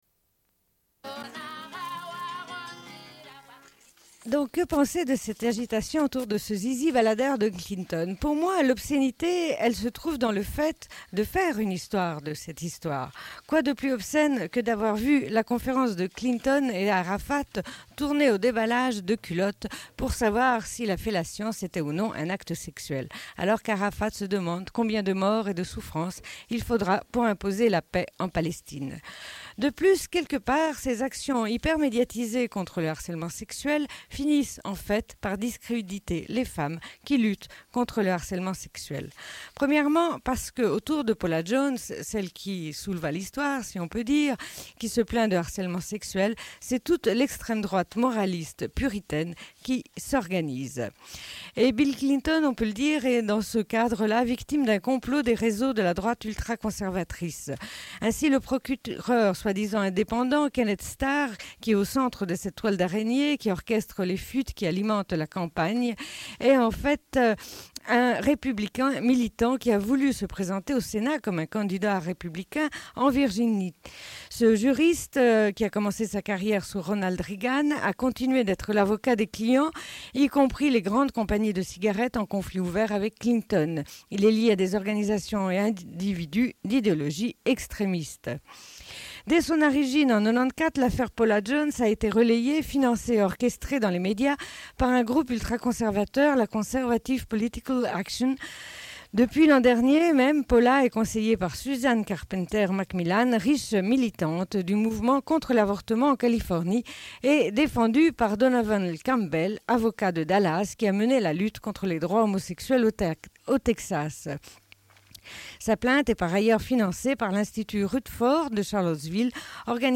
Bulletin d'information de Radio Pleine Lune du 28.01.1998 - Archives contestataires
Une cassette audio, face B